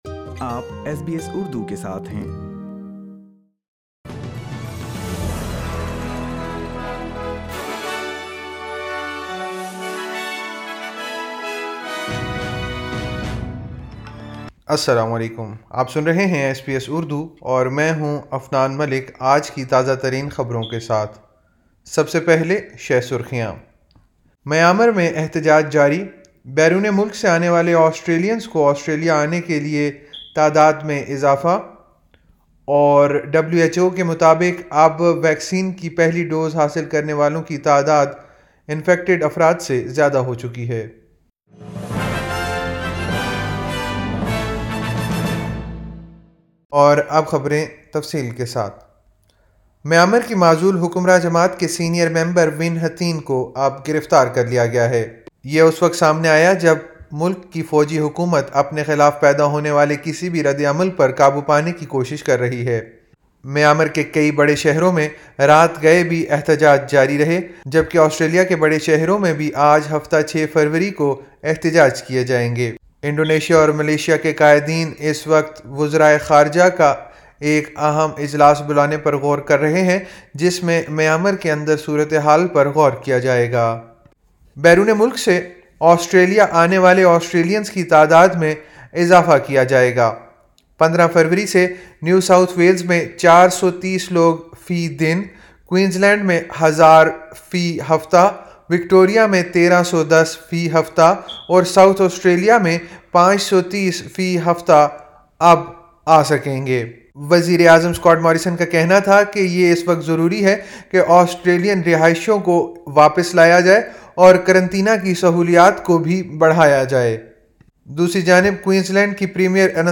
ایس بی ایس اردو خبریں 06 فروری 2021